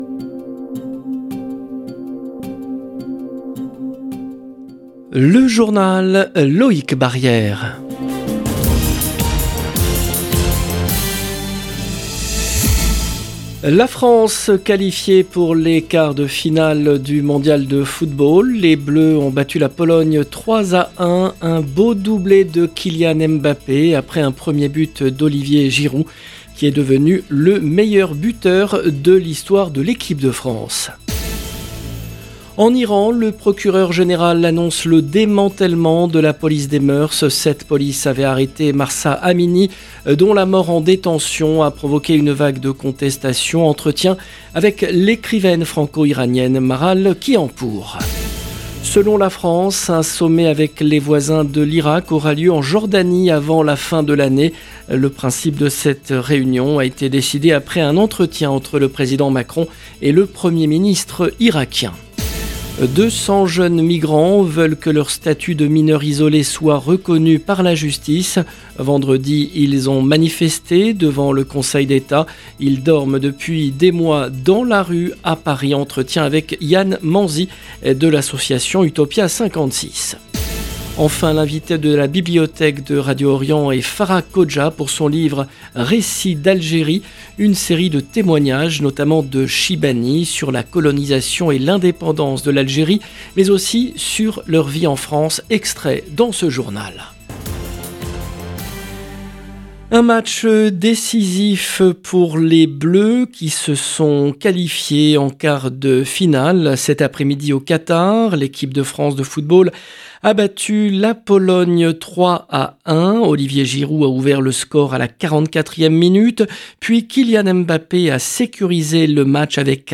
France Qatar M'Bappé Giroud 4 décembre 2022 - 17 min 47 sec LE JOURNAL DU SOIR EN LANGUE FRANCAISE DU 4/12/22 LB JOURNAL EN LANGUE FRANÇAISE La France qualifiée pour les quarts de finale du Mondial de football.